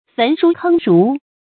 焚书坑儒 fén shū kēng rú
焚书坑儒发音
成语正音 焚，不能读作“fěn”。